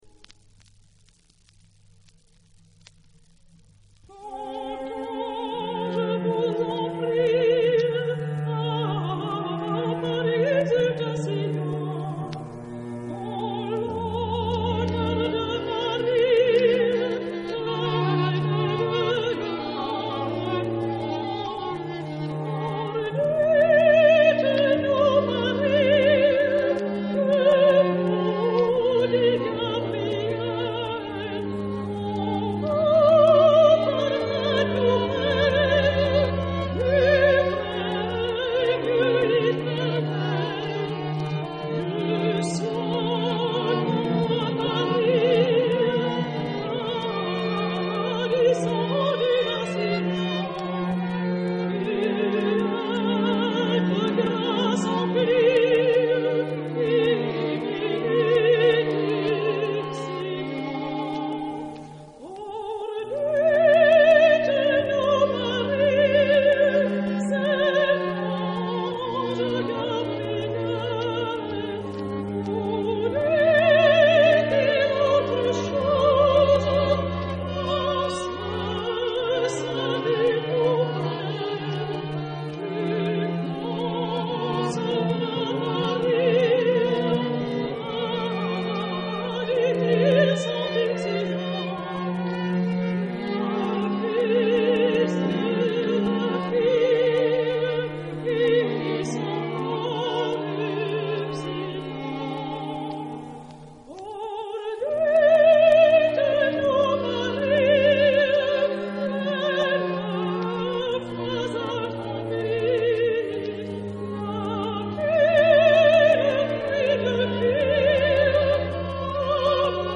Genre-Style-Forme : noël ; Profane ; contemporain
Type de choeur : SATB  (4 voix mixtes )
Tonalité : ré mineur